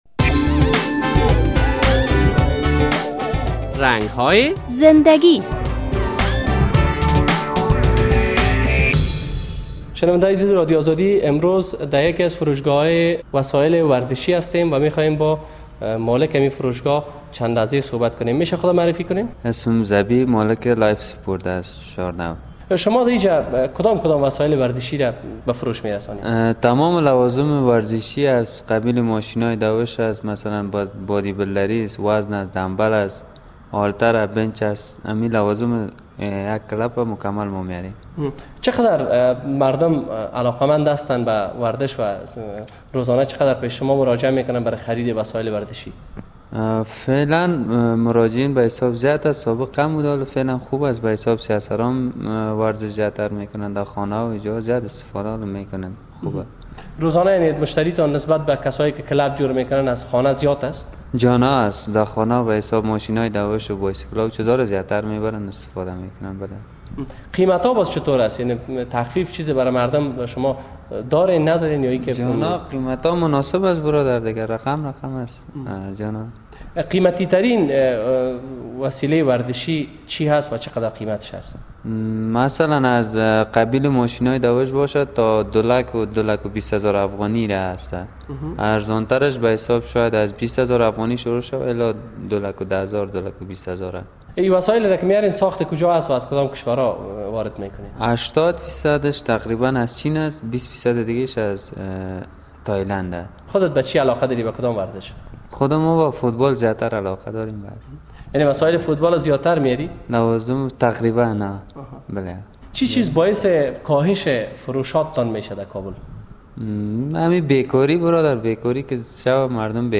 در این برنامهء رنگ های زنده گی با یک تن از دکاندارانی مصاحبه شده است که انواع و اقسام وسایل سپورتی را به فروش می رساند.